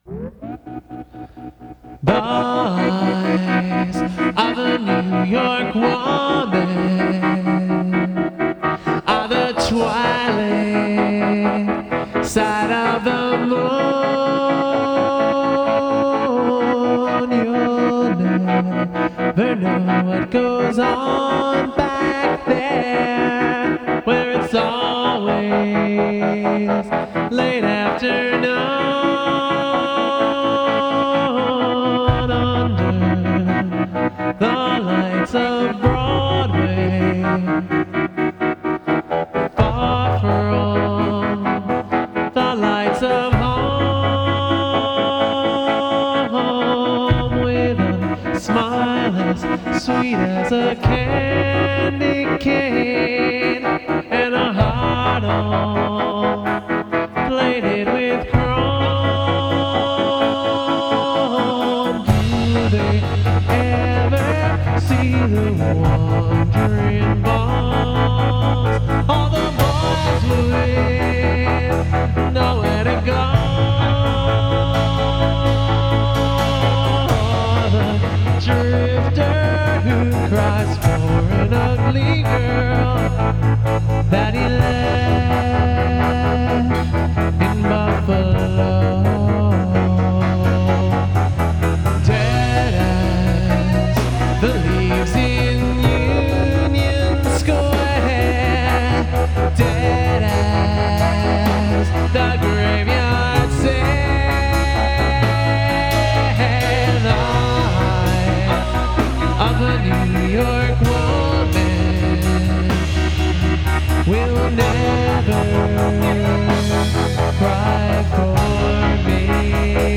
repeating the final line three times
rhythm and tremolo guitars, lead and backing vocals.
bass guitar, 6- and 12-string electric guitars.